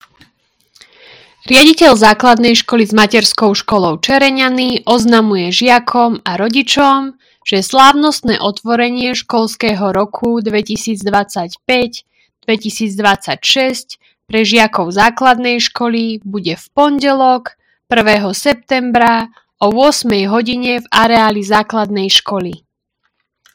Hlásenie obecného rozhlasu – Otvorenie Školského roka 2025/2026